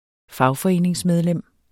Udtale [ ˈfɑwfʌˌeˀneŋs- ]